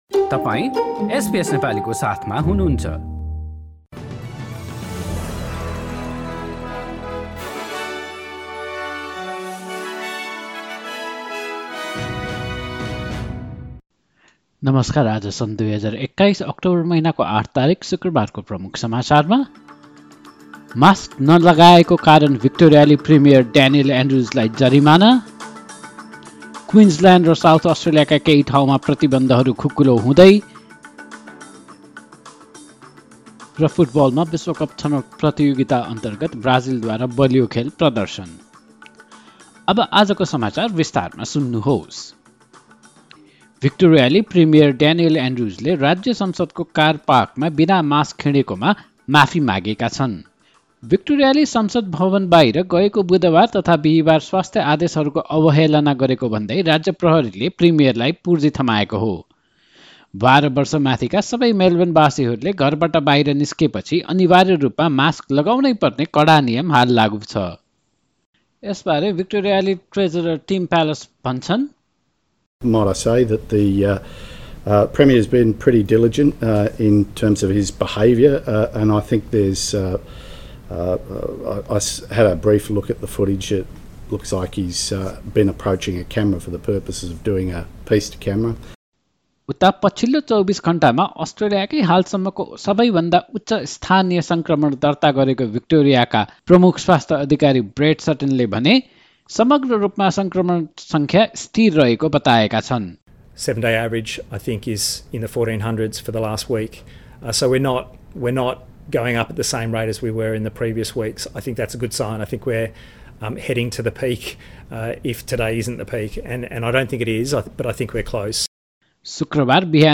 Listen to the latest news headlines from Australia in Nepali. In this bulletin, Victoria's Premier Daniel Andrews fined for not wearing a mask in Melbourne, health restrictions to ease in Queensland and in football Brazil maintains its strong record in the world cup qualifiers.